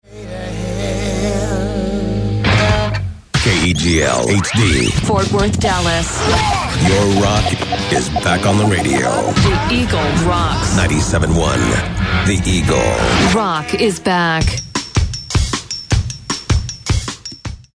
KEGL Top of the Hour Audio: